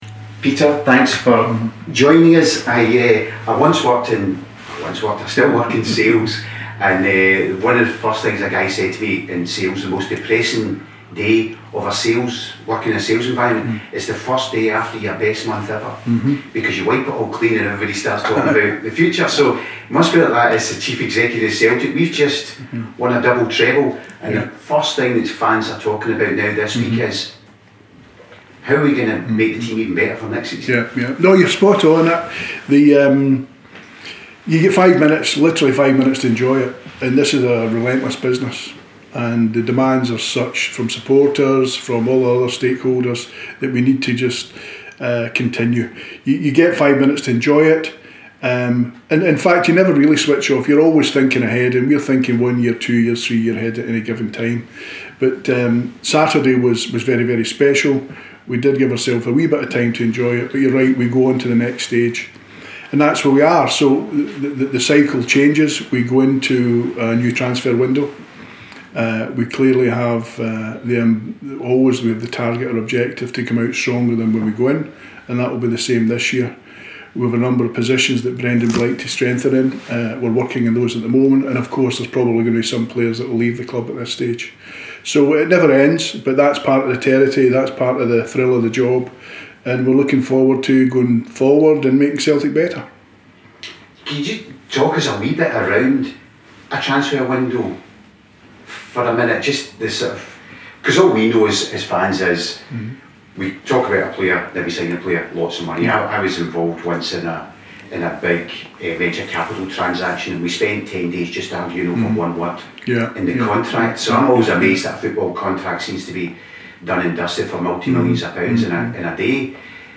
This week we are joined by the Celtic CEO. Yes big Pedro himself sat down on the joint CU & ByTheMin Couch to discuss all things Celtic.